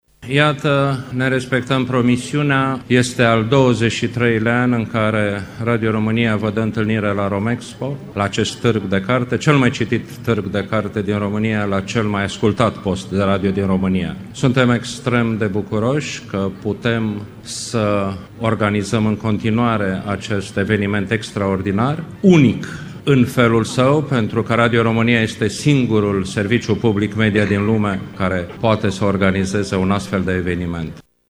Preşedintele director general al Societăţii Române de Radiodifuziune, Ovidiu Miculescu, a subliniat, alocuţiunea de deschidere pe care Radio România a transmis-o în direct, importanţa Târgului Gaudeamus în peisajul cultural românesc şi internaţional: